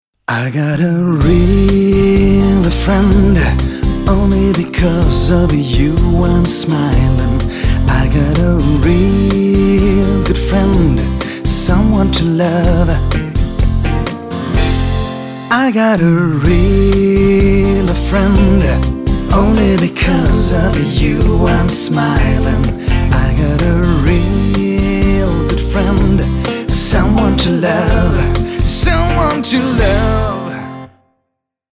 🐶 Brandsong für Unternehmen
👉 Emotional.
Positiv.
Verbindend.